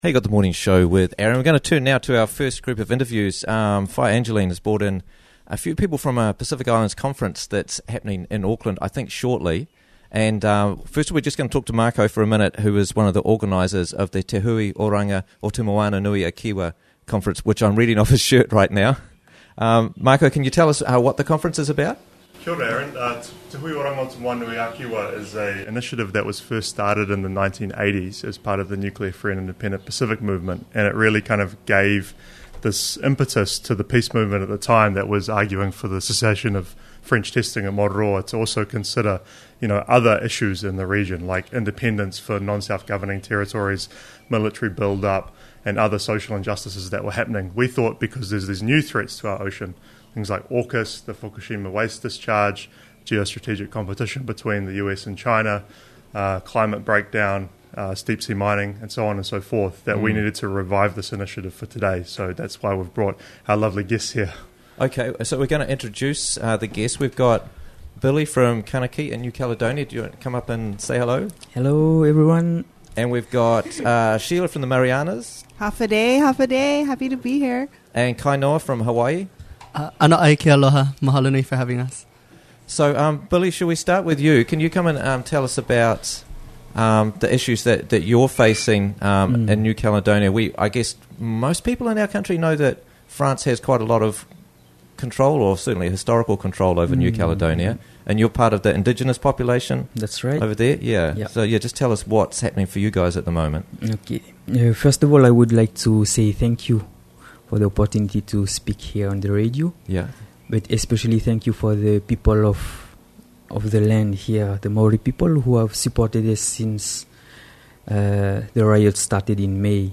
Pacific Independence Movement - Interviews from the Raglan Morning Show